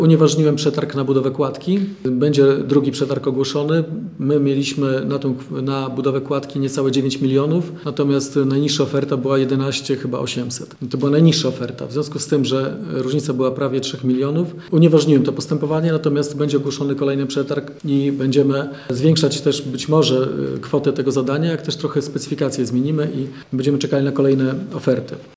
Kolejny przetarg na budowę kładki nad Kanałem Łuczańskim ogłosił giżycki ratusz. Jak informuje Radio 5 Wojciech Iwaszkiewicz, burmistrz miasta, poprzednio złożone oferty firm był za drogie.